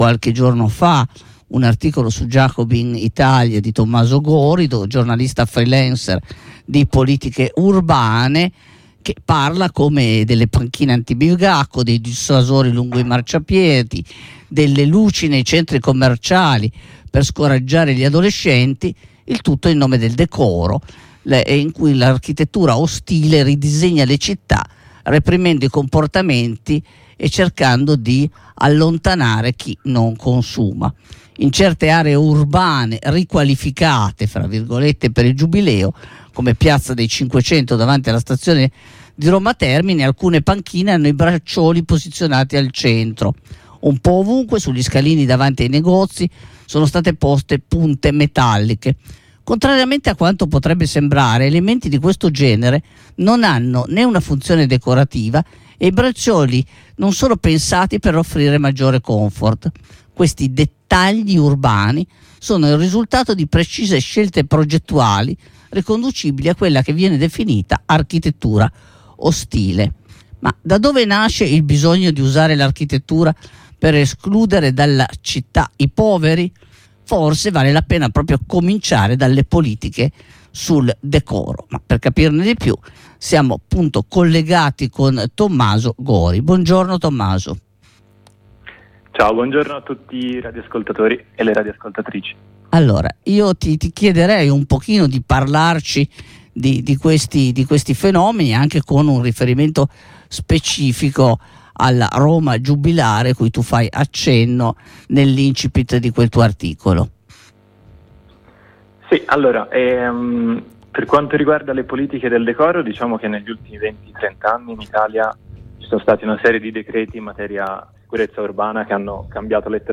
Ascolta la diretta: